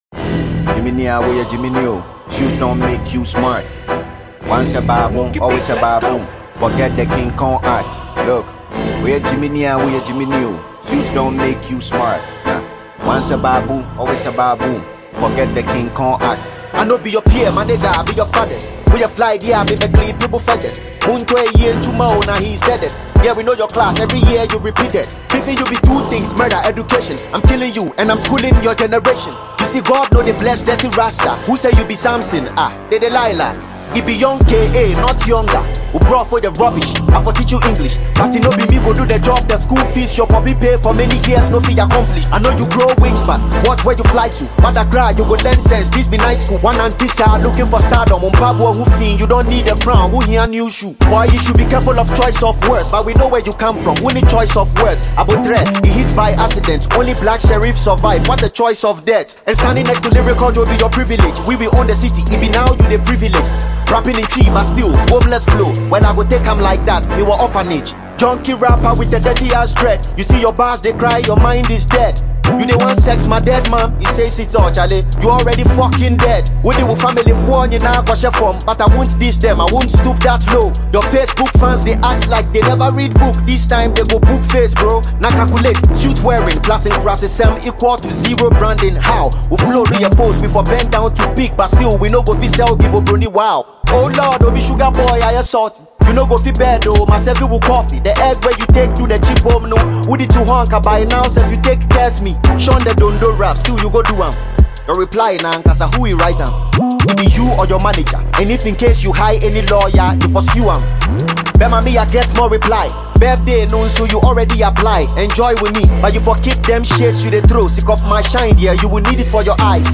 Ghana Music Music
diss song